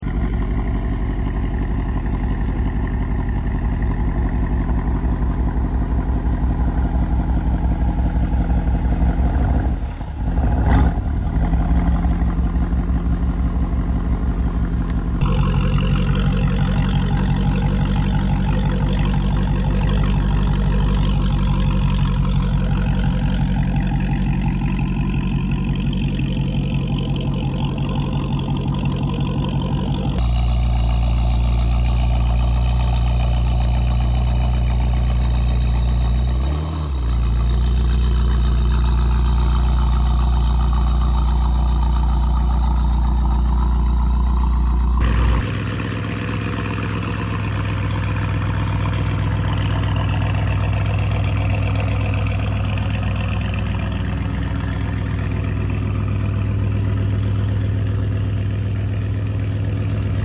V8 Exhaust Battle Comment which